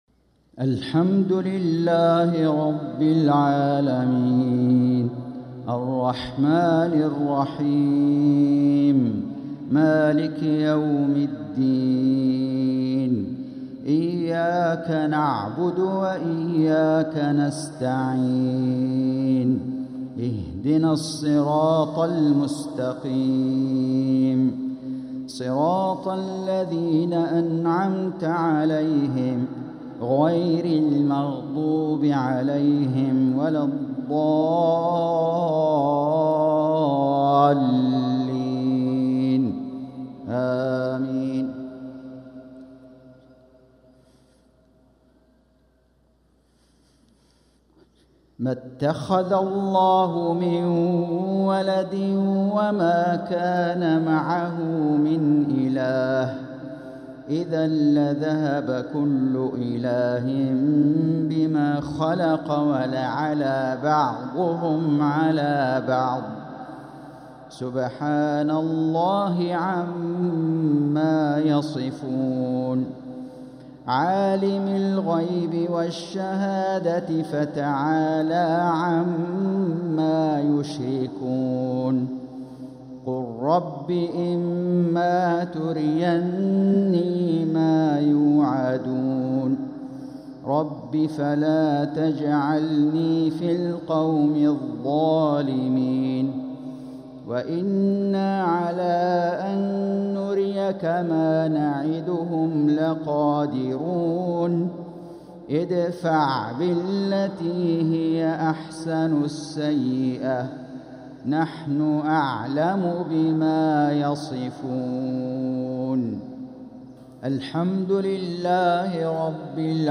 صلاة المغرب ٥-٧-١٤٤٦هـ | من سورة المؤمنون 91-100 | Maghrib prayer from Surah al-Mu'minun | 5-1-2025 > 1446 🕋 > الفروض - تلاوات الحرمين